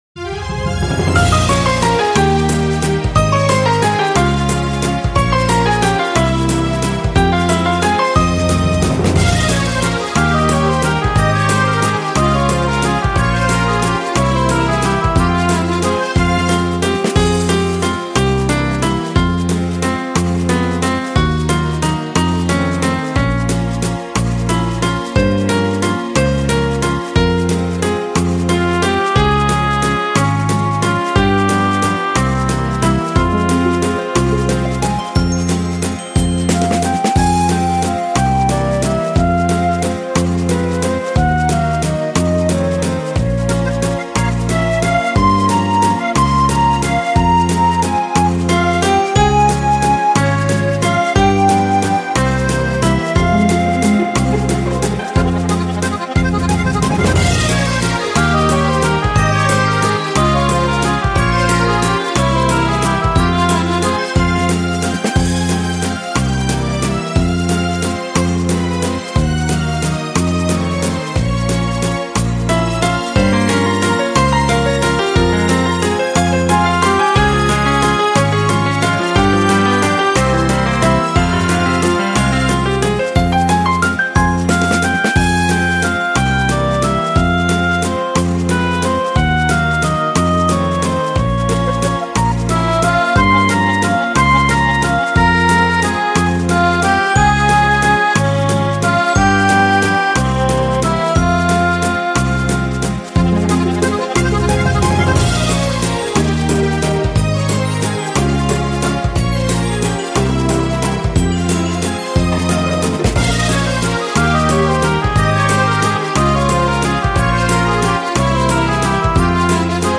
• Категория: Детские песни
Выпускной в детском саду
караоке
минусовка